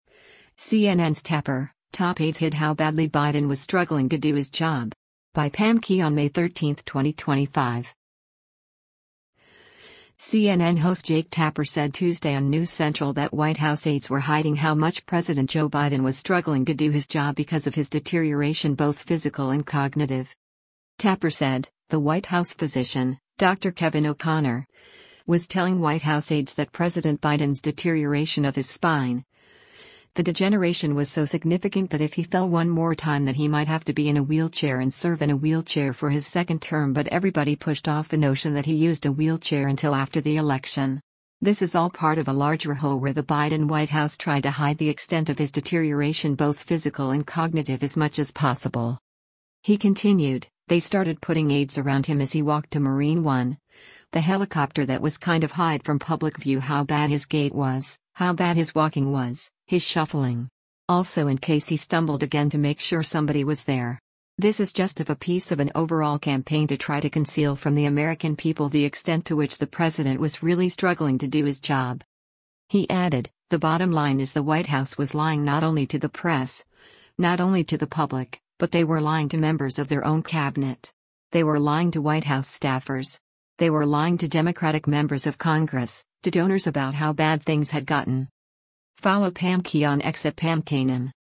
CNN host Jake Tapper said Tuesday on “News Central” that White House aides were hiding how much President Joe Biden was struggling to do his job because of his “deterioration both physical and cognitive.”